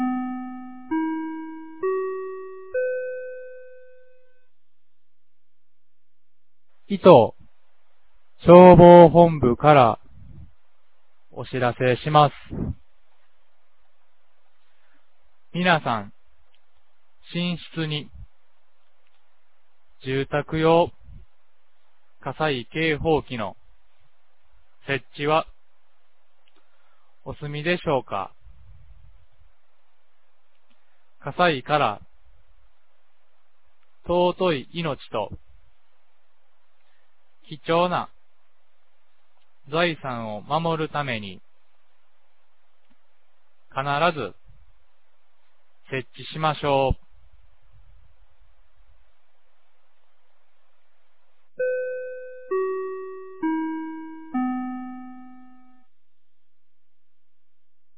2024年10月21日 10時01分に、九度山町より全地区へ放送がありました。